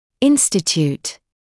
[‘ɪnstɪtjuːt][‘инститйуːт]институт; устанавливать; учреждать